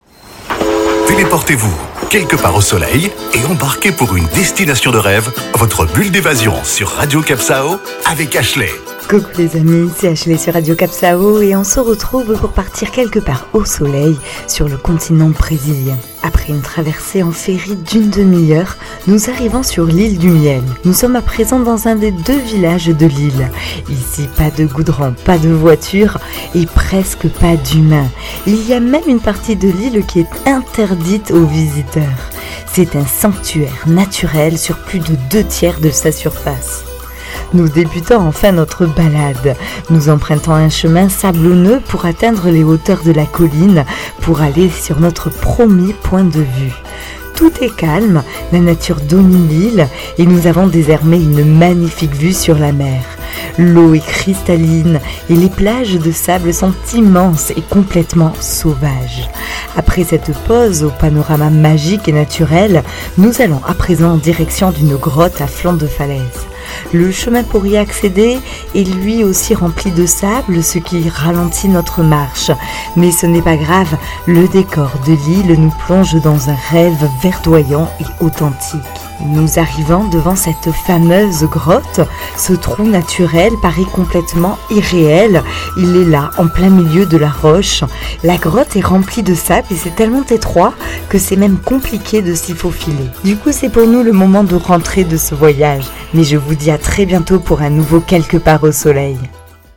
Carte postale sonore : respirez l'air de cette île du miel en forme de poumon, et à la beauté sauvage. Un sanctuaire naturel qui vous apportera quiétude et authenticité.